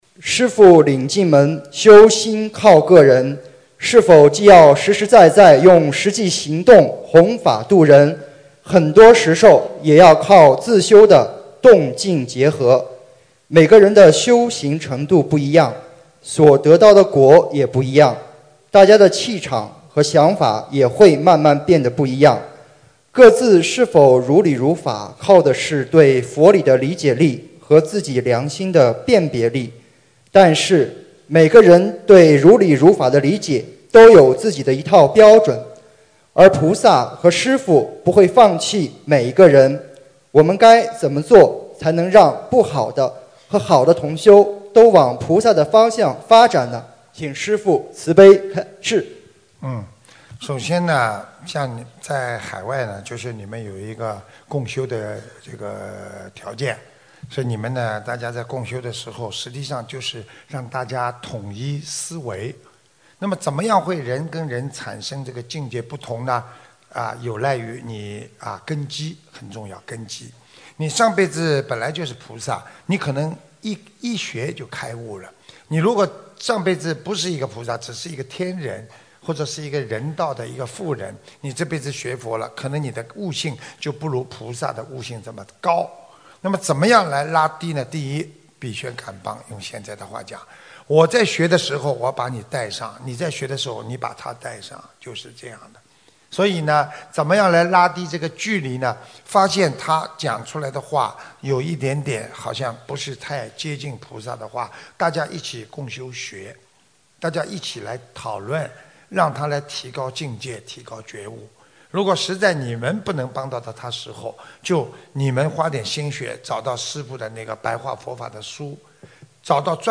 如何帮助不同根基的同修学佛┃弟子提问 师父回答 - 2017 - 心如菩提 - Powered by Discuz!